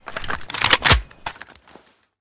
aks74_unjam.ogg